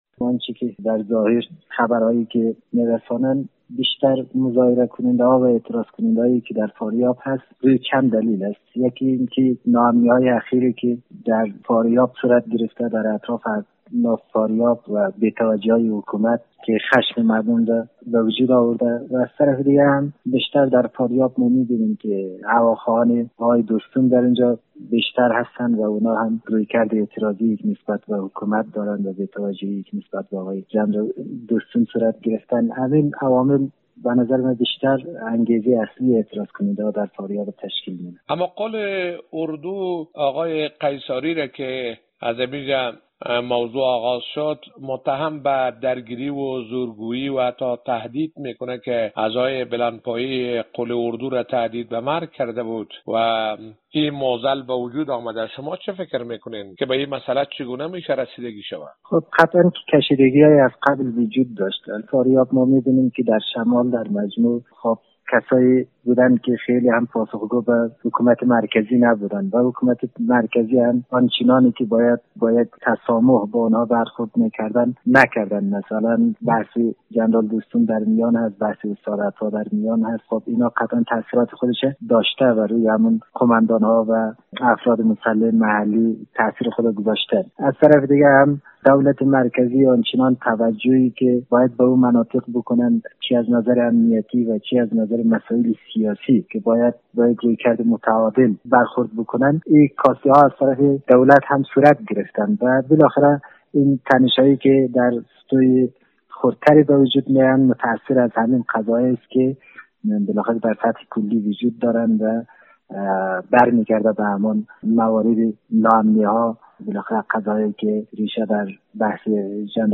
کارشناس سیاسی افغان